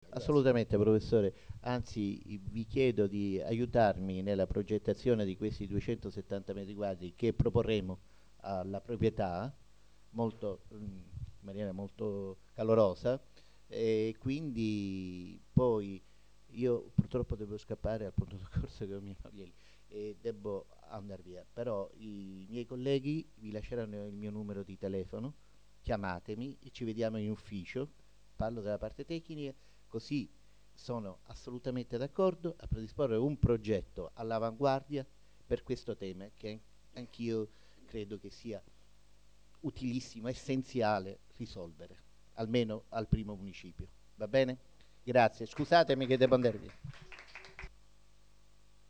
Registrazione integrale dell'incontro svoltosi il 4 ottobre 2012 presso la sala riunioni di Via Boncompagni, 71